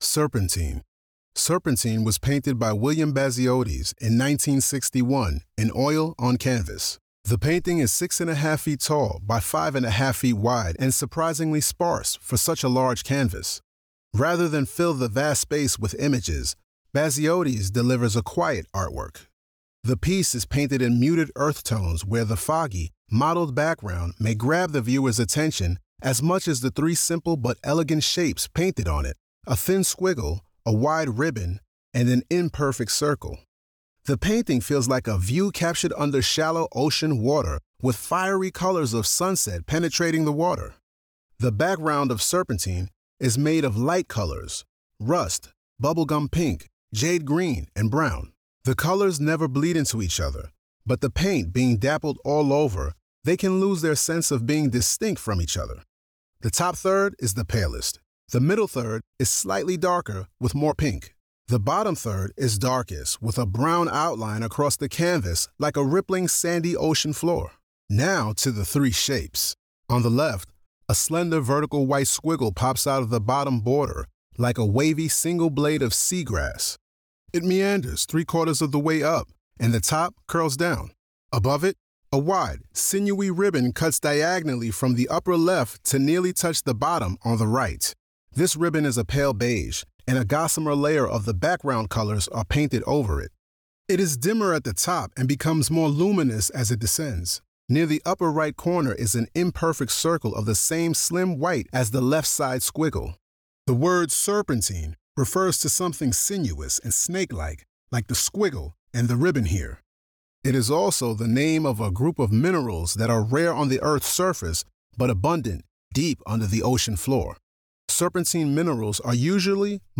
Audio Description (02:23)